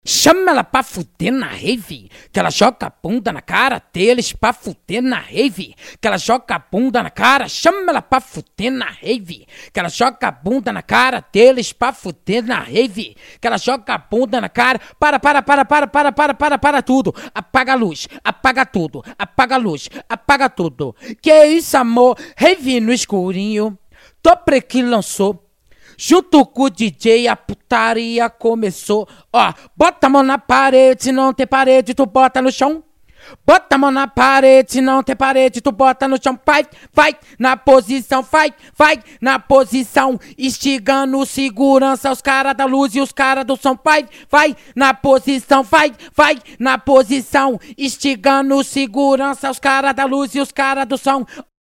Acapella de Funk